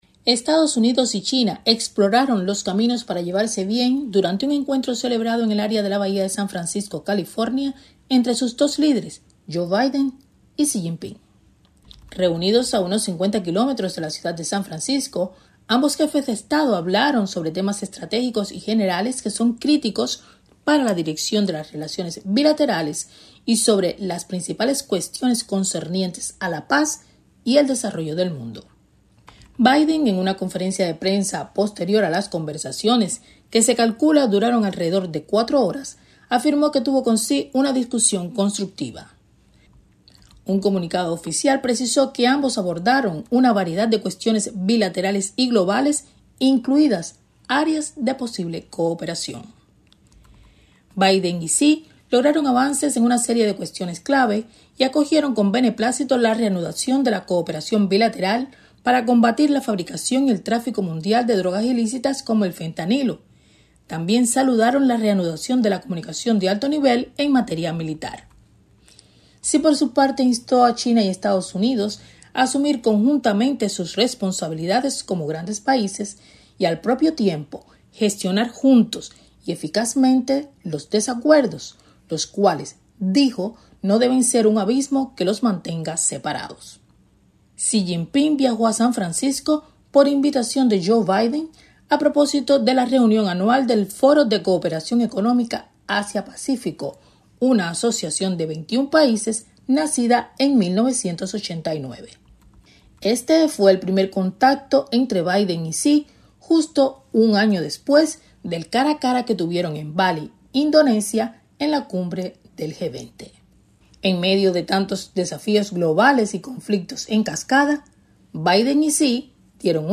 desde Washington